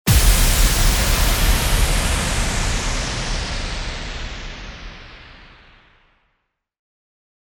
FX-1495-IMPACT
FX-1495-IMPACT.mp3